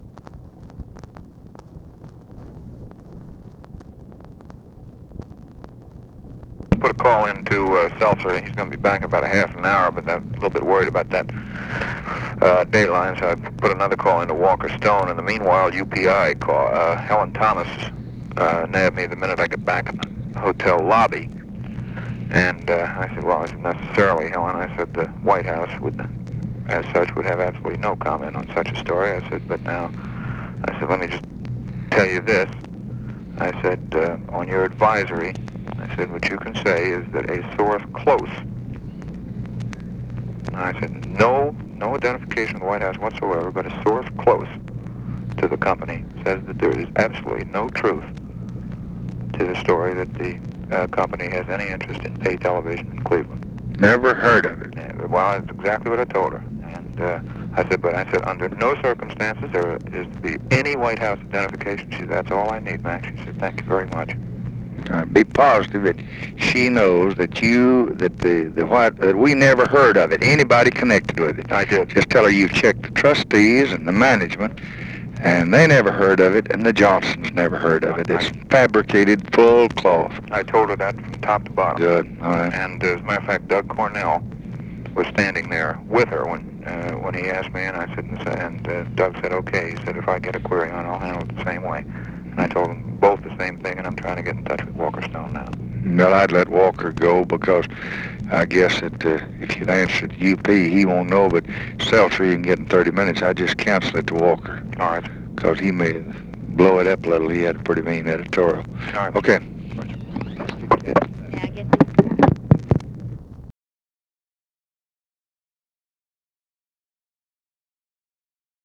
Conversation with MAC KILDUFF, November 12, 1964